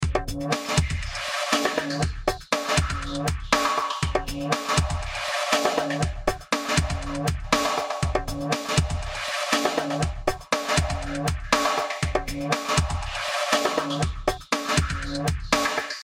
描述：部落鼓的循环
Tag: 120 bpm Electronic Loops Drum Loops 2.69 MB wav Key : Unknown